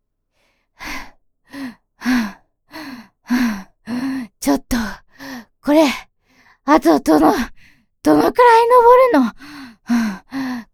ハコネクト所属メンバーが収録した「夏のおでかけ」をテーマにしたコンセプトボイスを是非お楽しみください！
ボイスサンプル